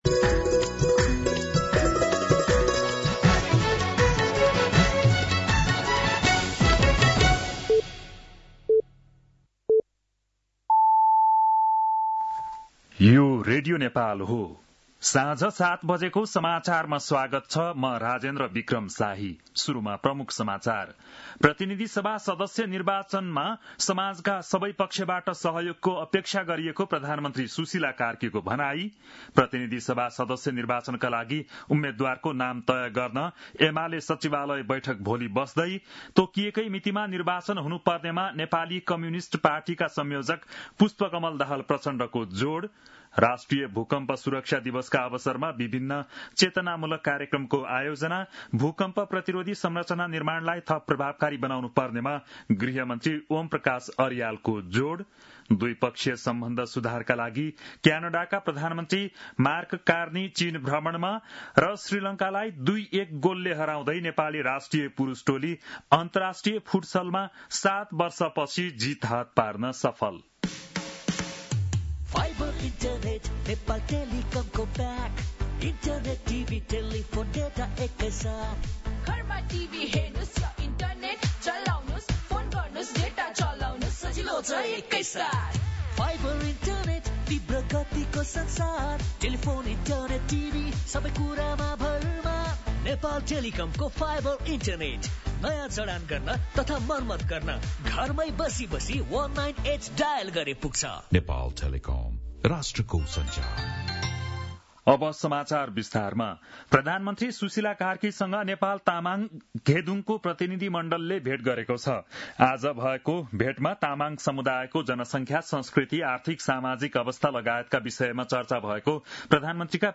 बेलुकी ७ बजेको नेपाली समाचार : २ माघ , २०८२